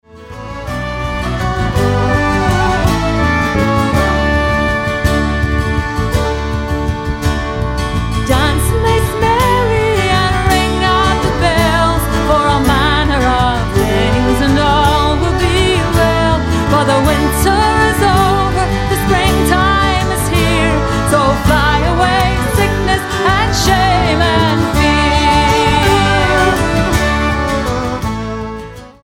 STYLE: Celtic
is catchy with is upbeat Celtic folk arrangement